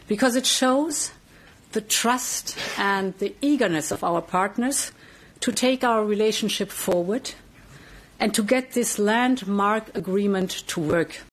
She says the backing of countries in South America is an important development: